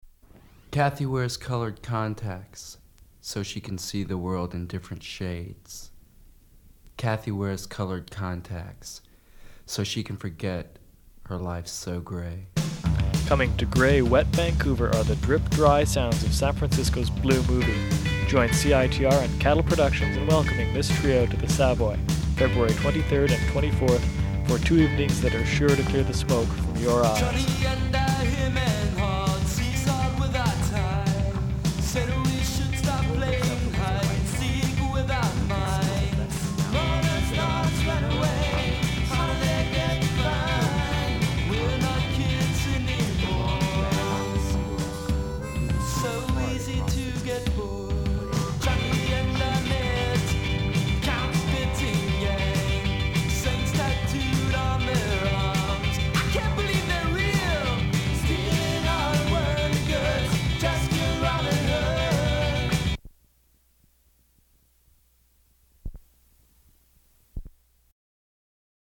Recording of a promotional spot for a live performance by the San Francisco-based musical group Blue Movie.